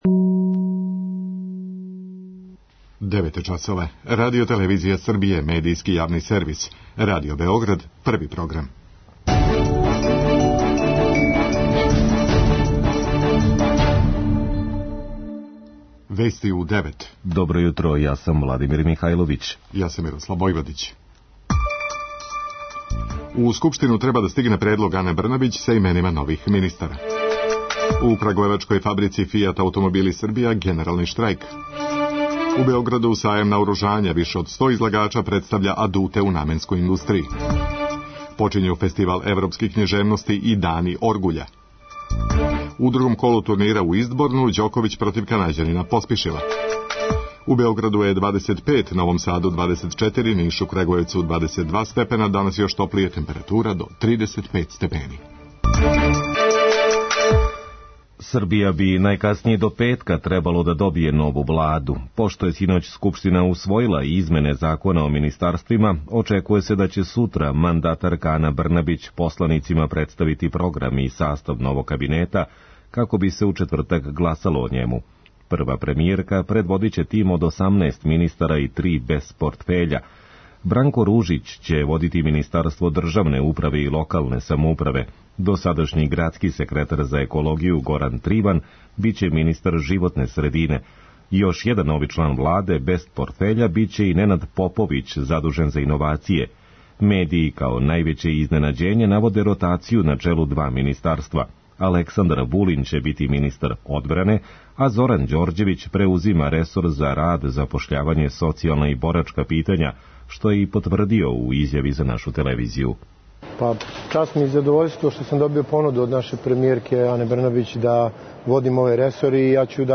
преузми : 3.69 MB Вести у 9 Autor: разни аутори Преглед најважнијиx информација из земље из света.